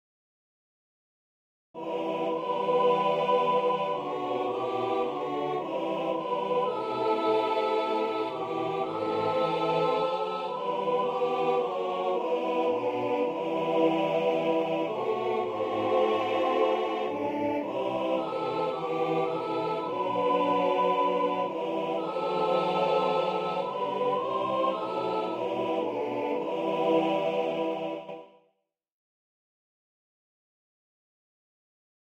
A hymn setting